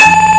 Doors Cloche Sound Effect Free Download
Doors Cloche